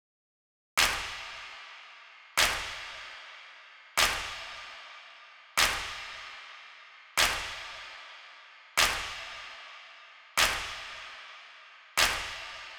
ROOM CLAP LOOP 150 BPM.wav